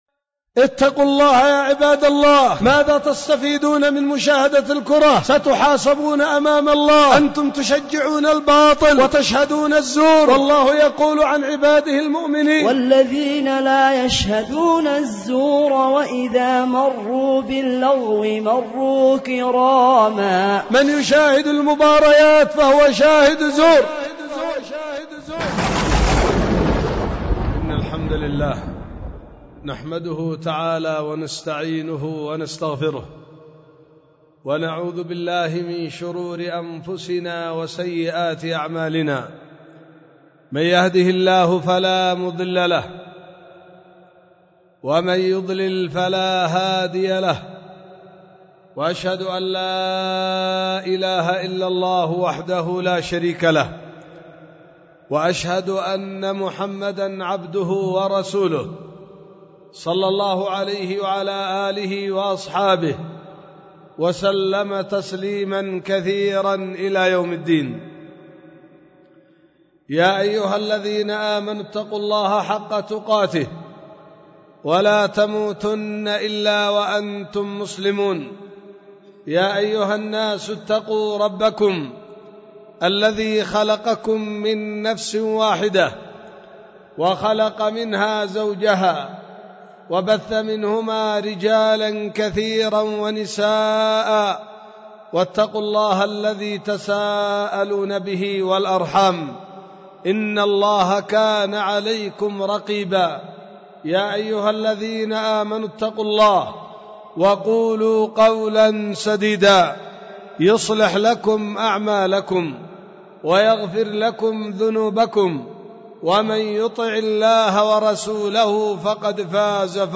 خطبة
في دار الحديث بوادي بنا – السدة – إب – اليمن